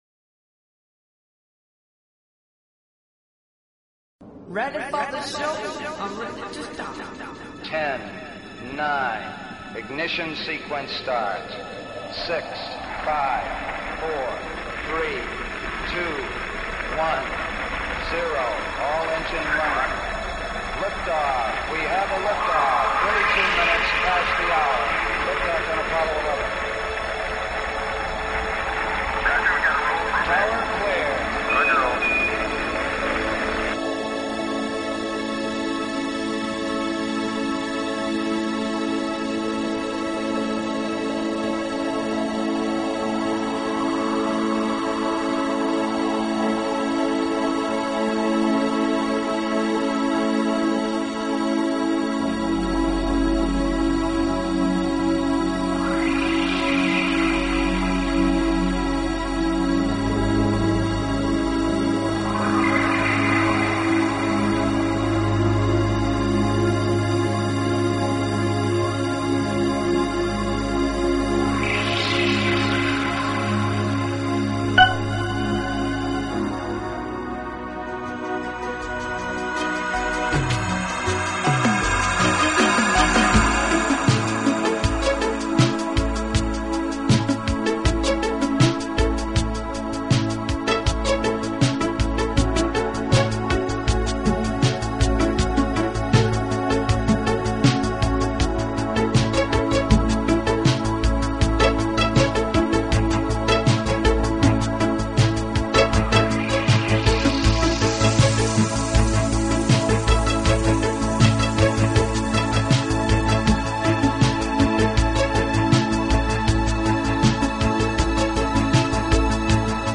Talk Show Episode, Audio Podcast, ET-First_Contact_Radio and Courtesy of BBS Radio on , show guests , about , categorized as